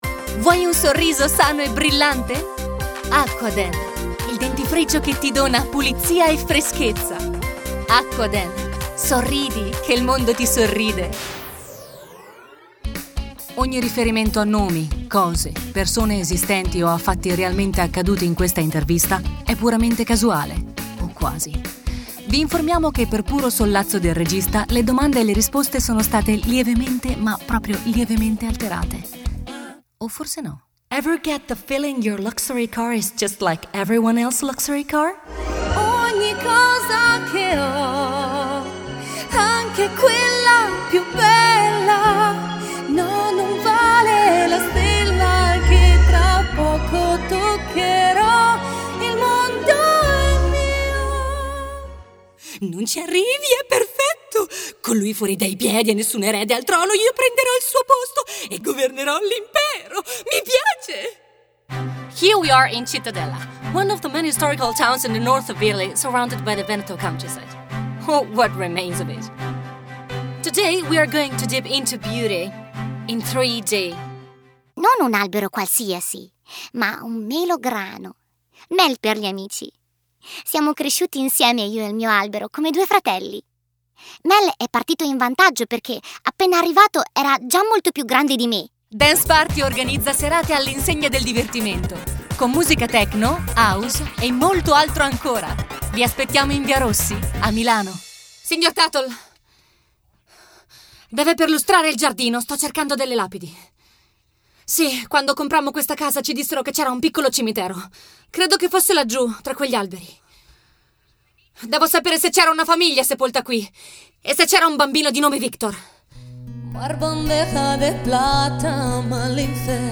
Voce giovanile e accattivante.
Sprechprobe: Werbung (Muttersprache):